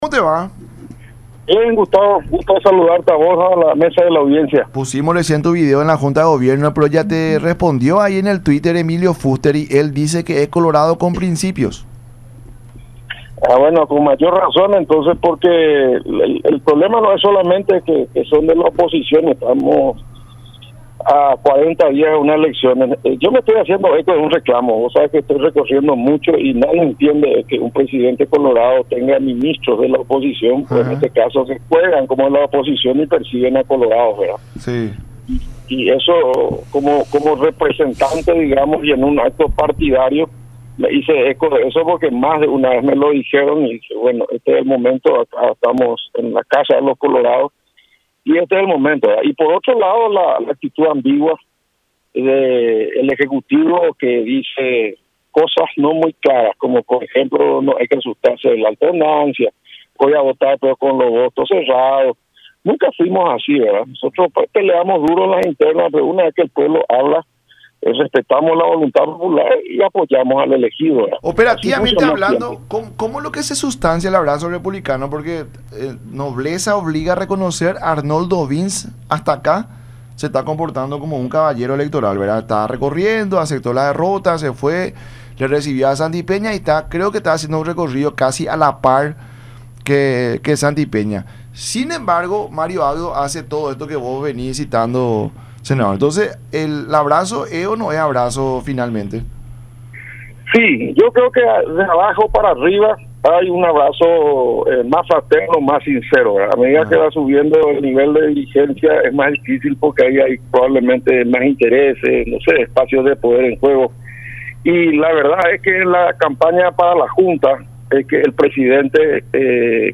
Ante esas palabras, añadió en charla con La Unión Hace La Fuerza a través de Unión TV y radio La Unión: “que Abdo mande una señal inequívoca de que está realmente con el Partido”.